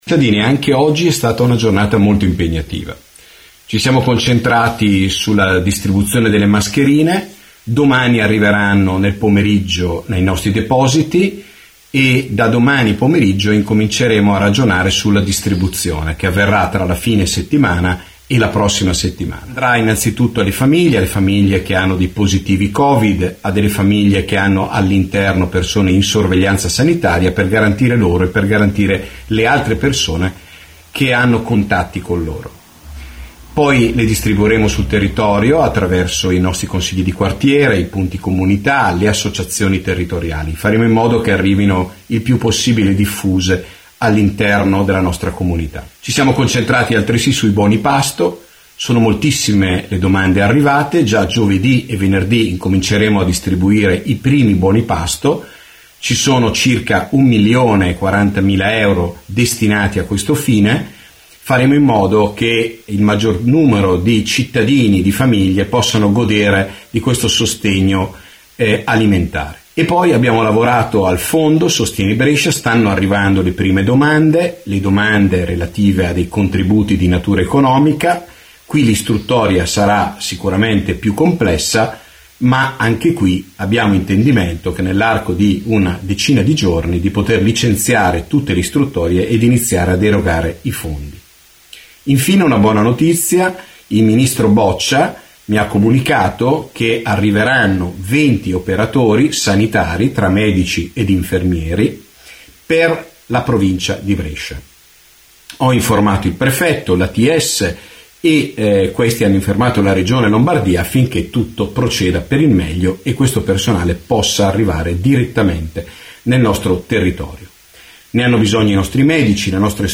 RADIOGIORNALI